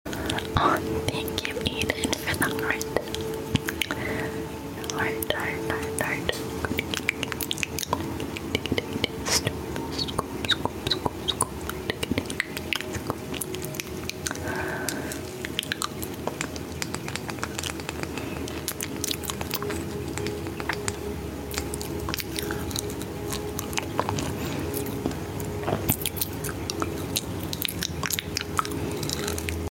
ASMR edible spit painting ! sound effects free download